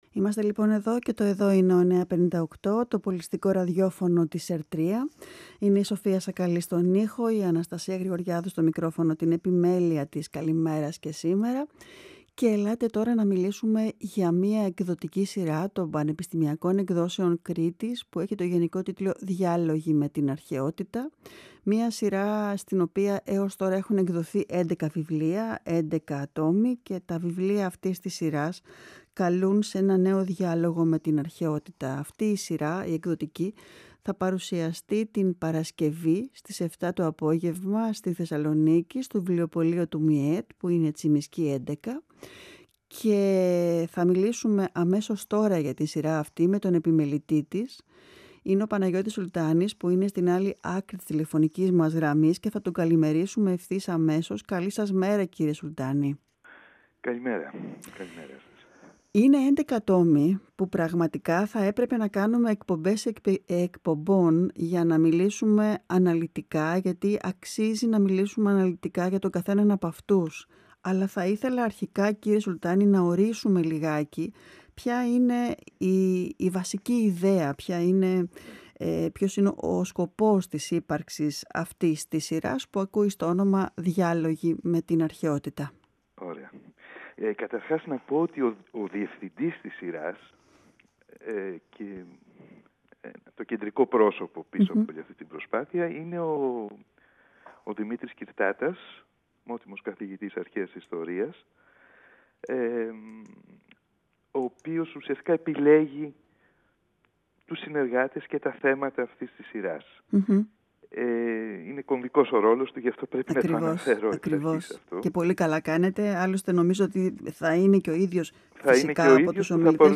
Συνέντευξη
Η συνέντευξη πραγματοποιήθηκε την Τετάρτη 6/3/2024 εκπομπή “καλημέρα” στον 9,58fm της ΕΡΤ3